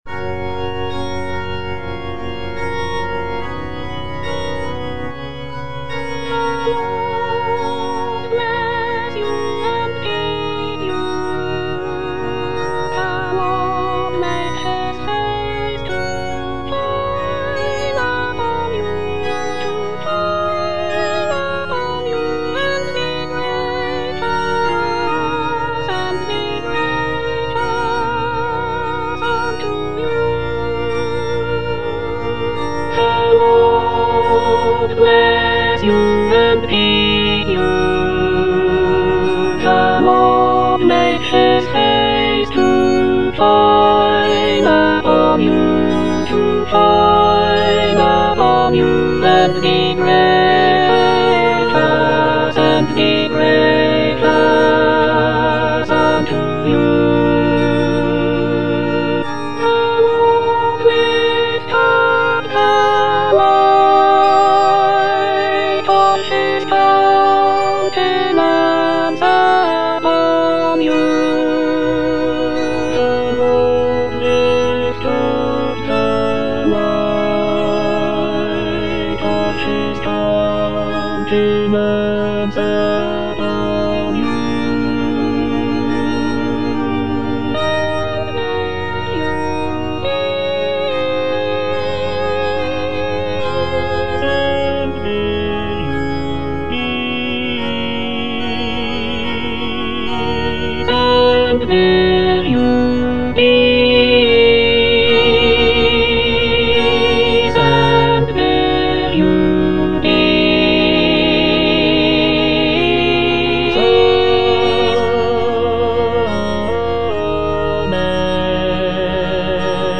Alto (Emphasised voice and other voices)
choral benediction